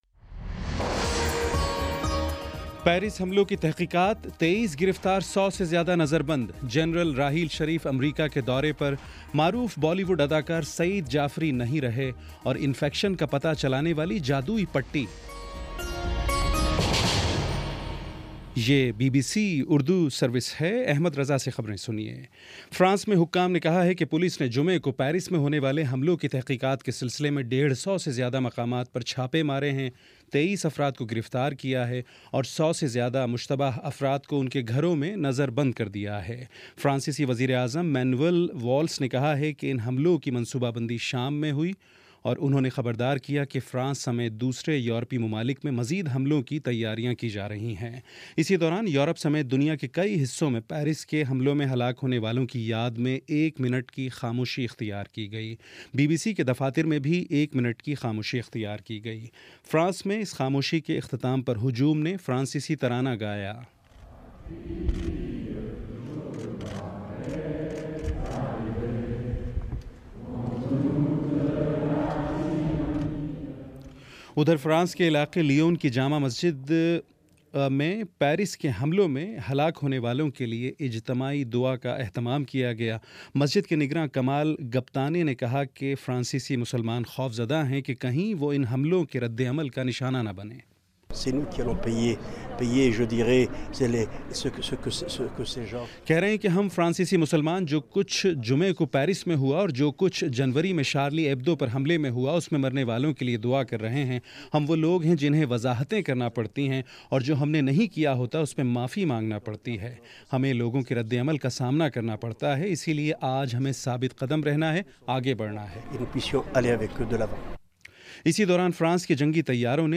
نومبر 16 : شام پانچ بجے کا نیوز بُلیٹن